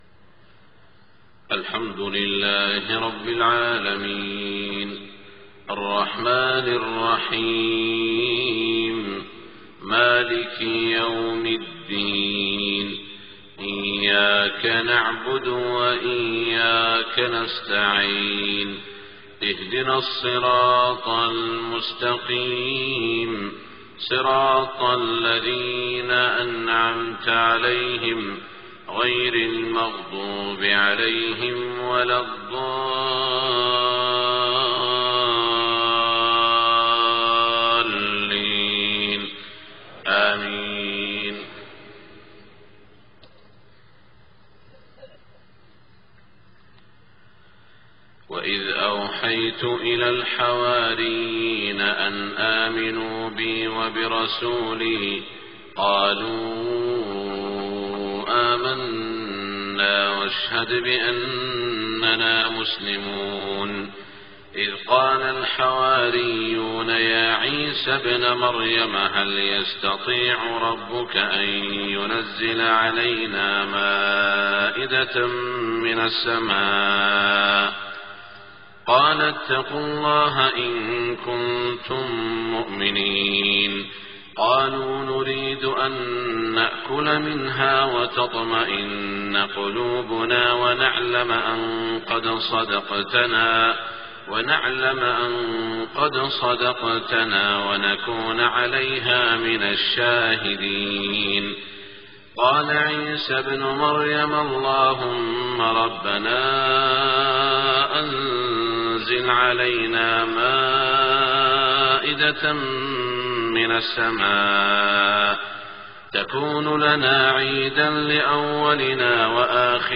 صلاة الفجر 9-5-1427 من سورتي المائدة و الأنعام > 1427 🕋 > الفروض - تلاوات الحرمين